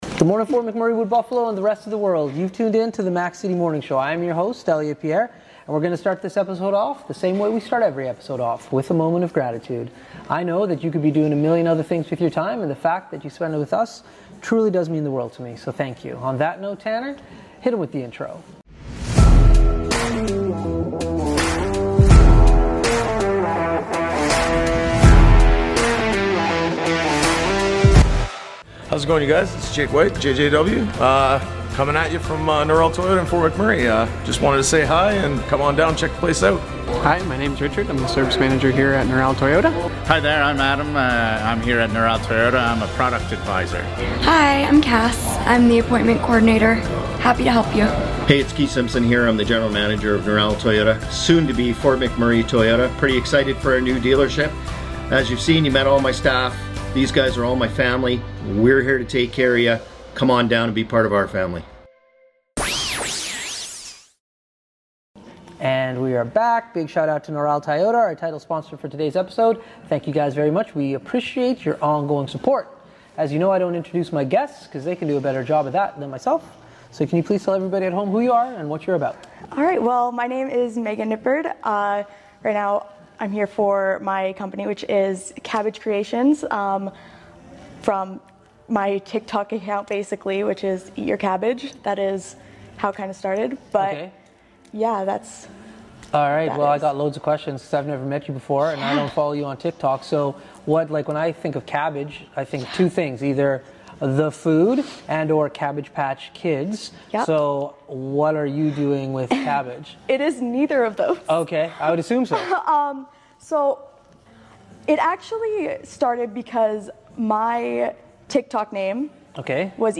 We are back on location